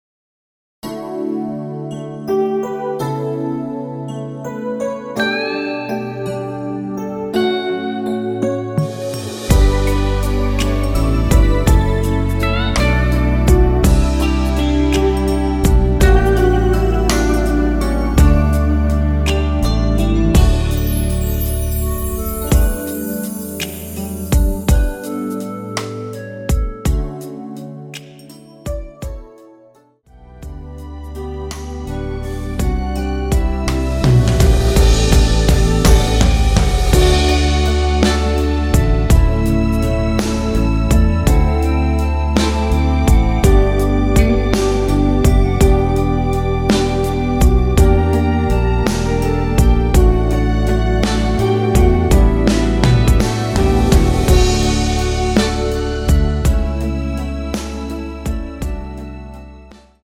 원키 멜로디 포함된 MR입니다.(미리듣기 참조)
D
앞부분30초, 뒷부분30초씩 편집해서 올려 드리고 있습니다.
중간에 음이 끈어지고 다시 나오는 이유는